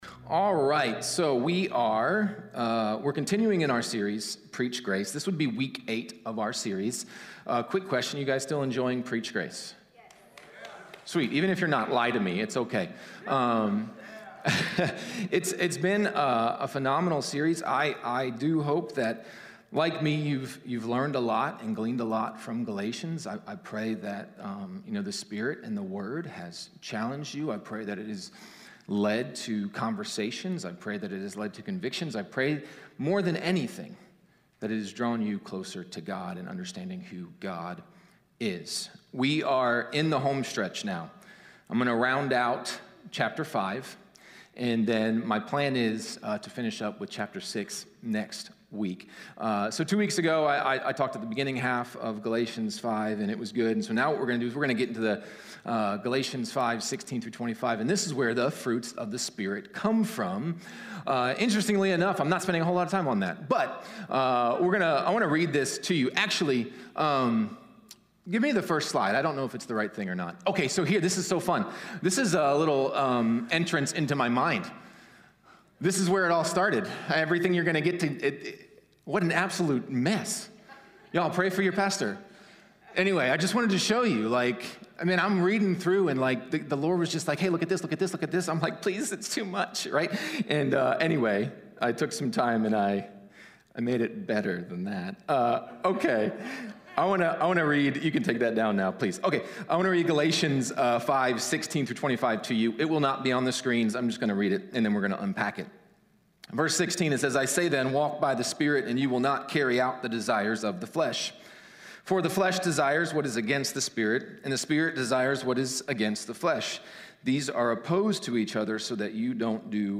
Sermons | Kairos Church